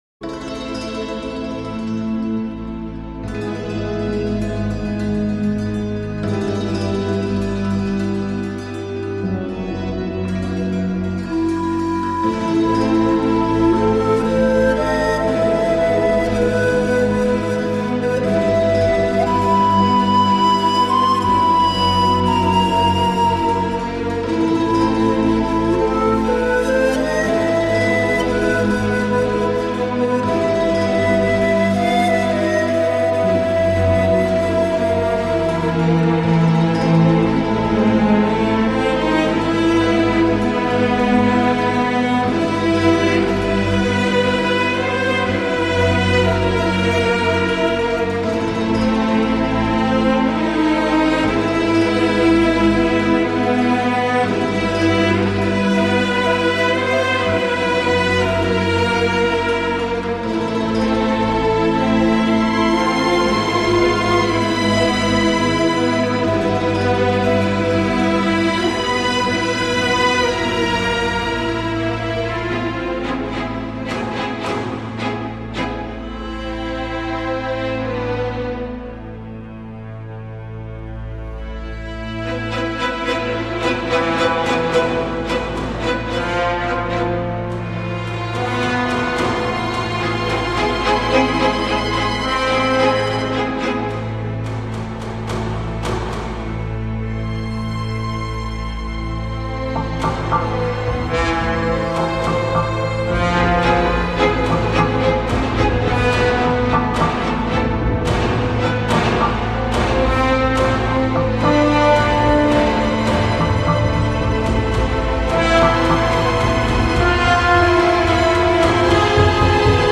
dont l’utilisation d’un chœur